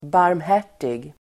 Uttal: [barmh'är_t:ig]